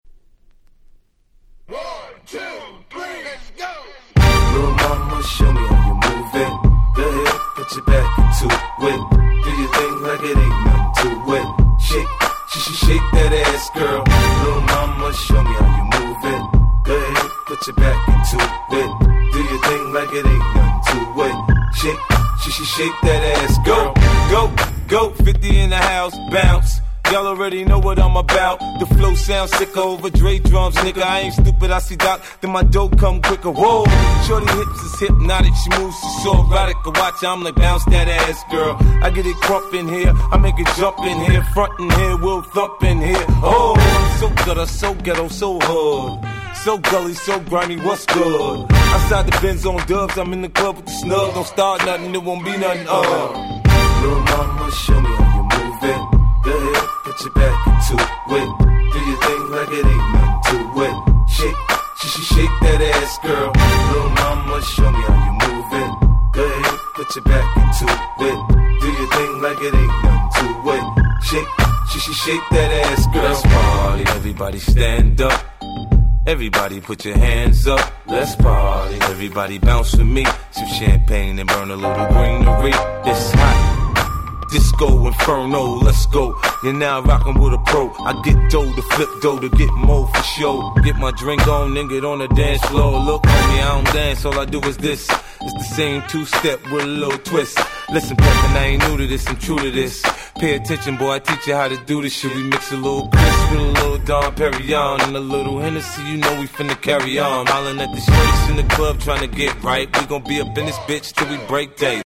04' Super Hit Hip Hop !!